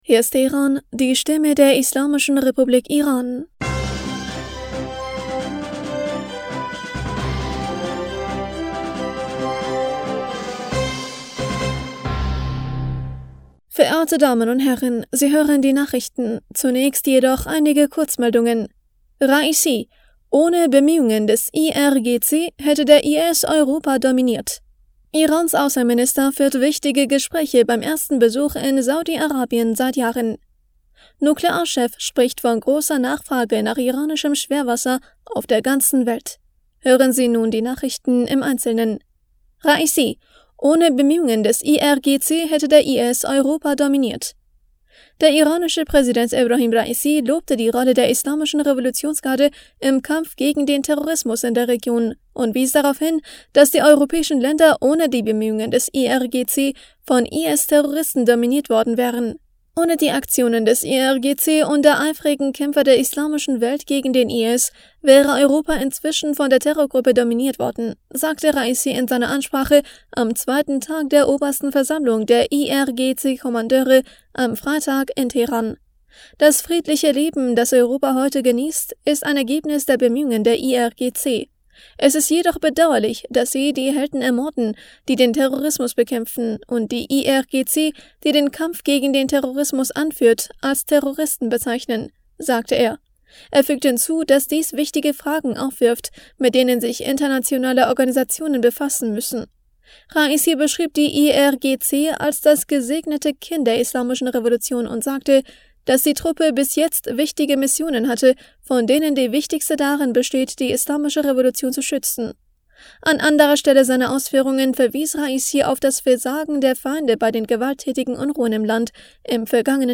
Nachrichten vom 18. August 2023
Die Nachrichten von Freitag, dem 18. August 2023